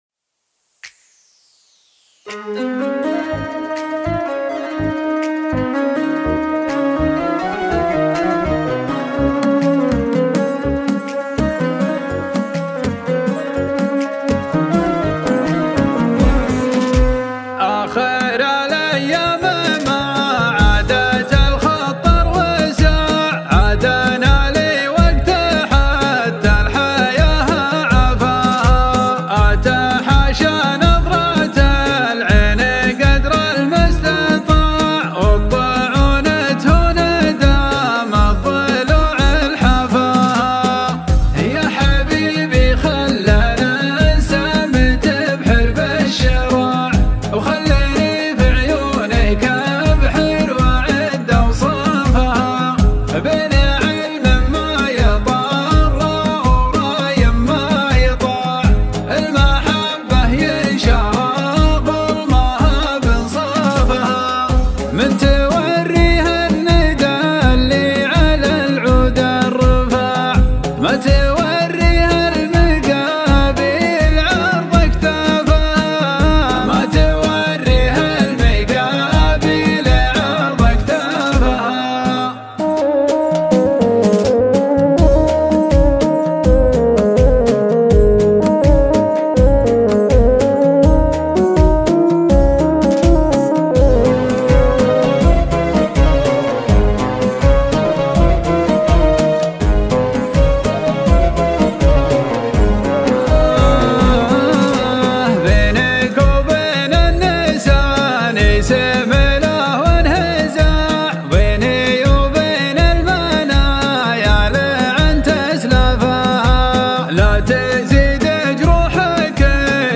شيلات طرب